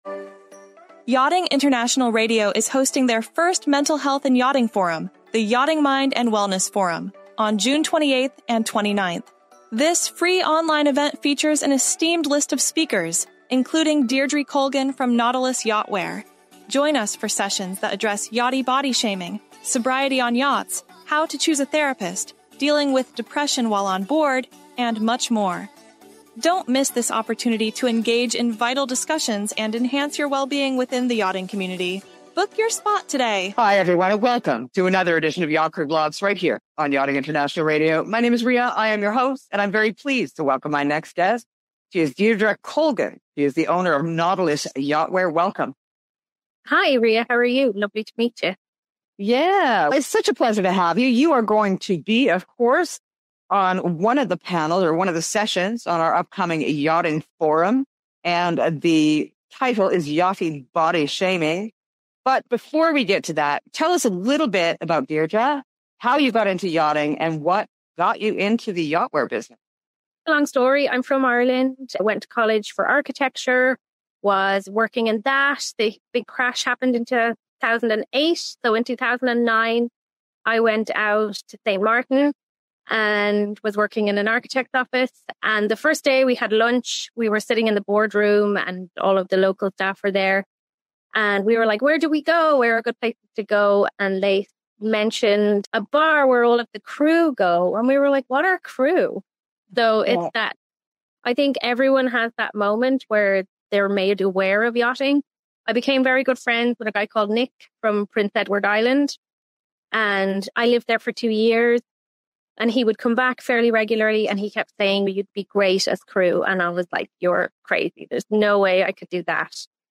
Don't miss this engaging conversation packed with valuable insights!